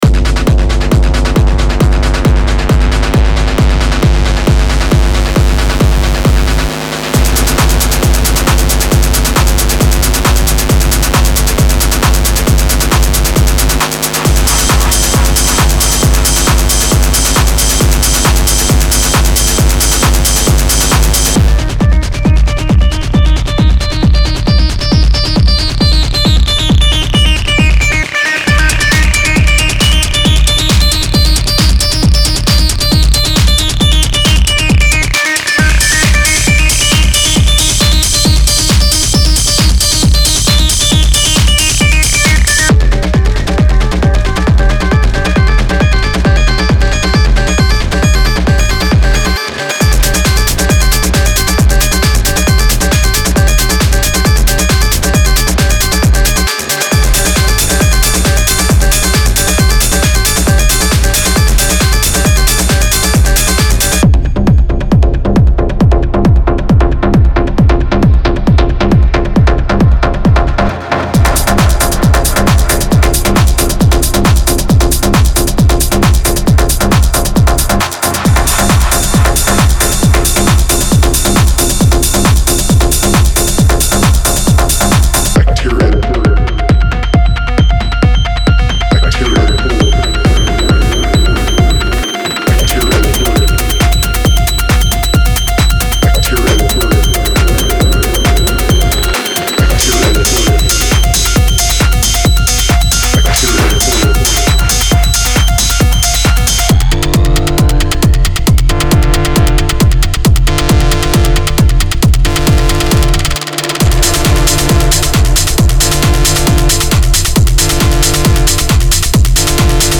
デモサウンドはコチラ↓
Genre:Techno